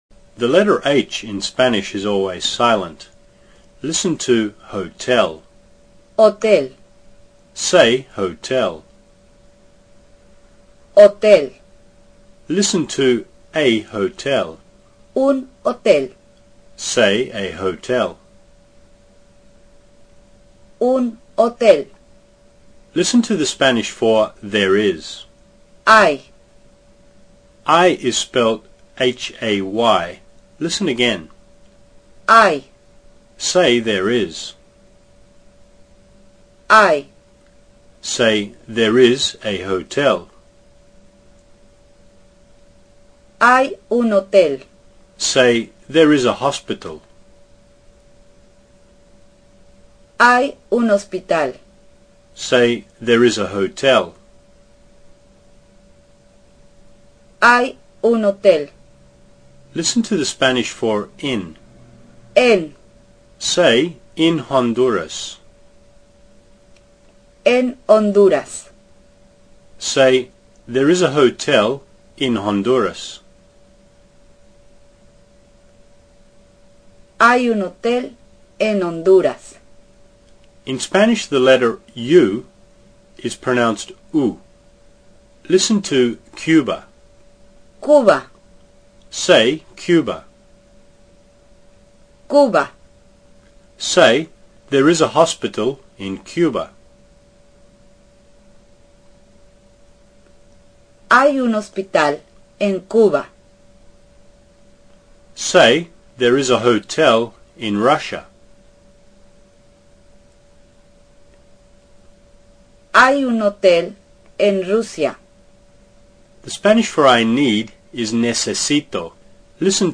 Learn Spanish mp3 pronunciation course part 1